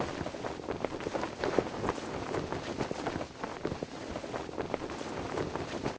target_wind_float_clothloop.ogg